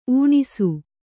♪ ūṇisu